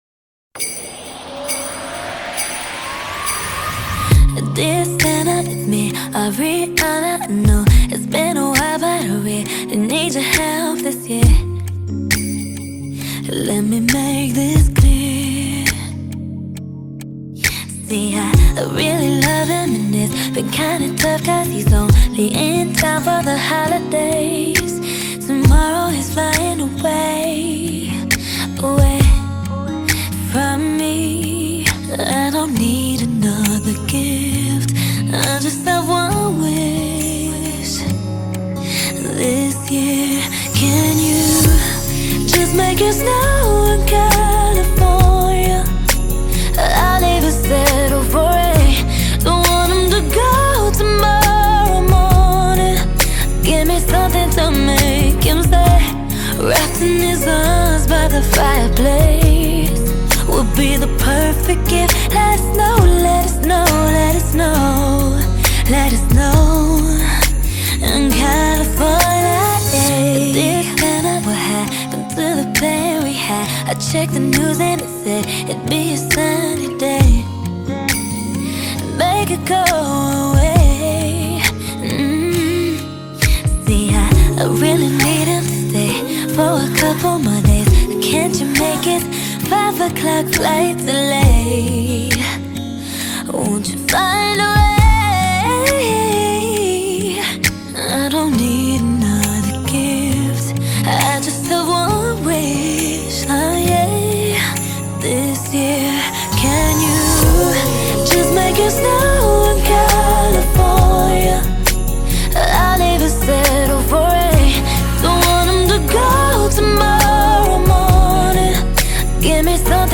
یه آهنگ آروم و احساسی